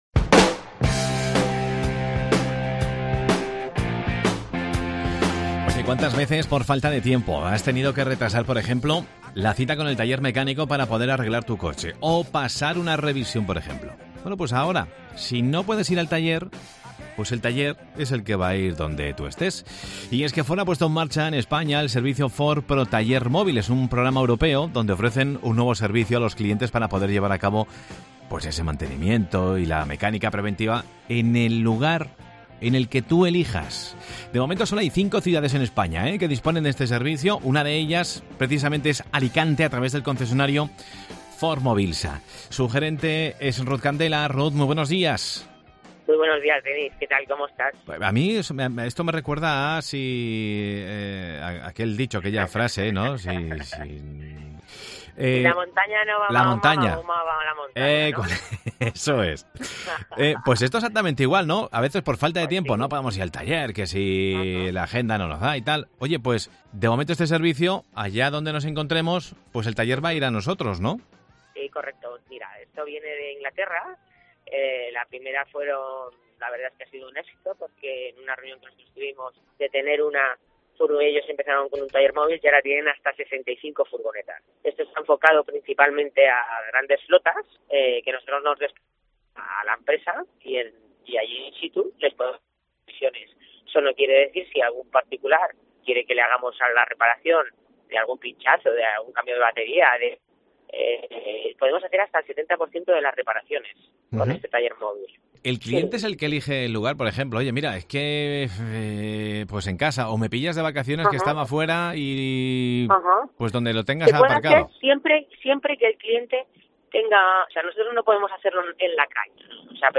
La marca del óvalo pone en marcha el quinto taller móvil en España a través del concesionario Ford Movilsa. Escucha la entrevista